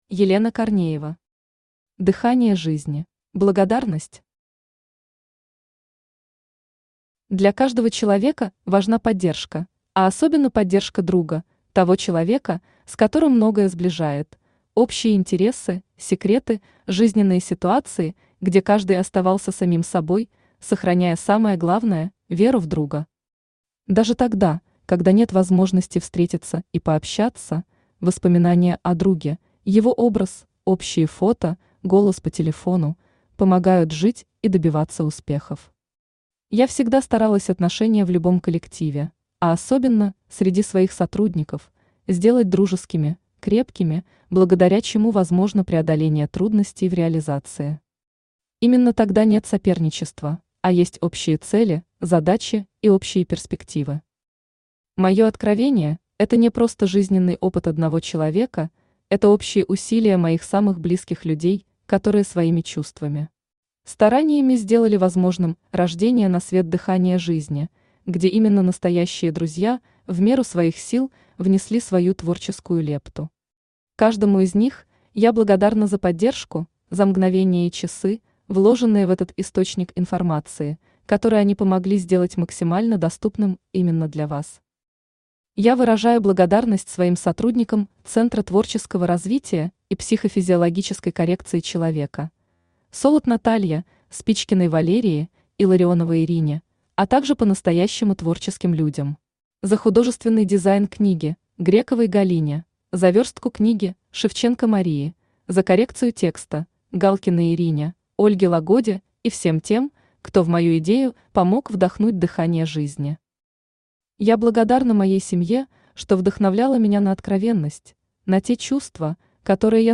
Аудиокнига Дыхание жизни | Библиотека аудиокниг
Aудиокнига Дыхание жизни Автор Елена Корнеева Читает аудиокнигу Авточтец ЛитРес.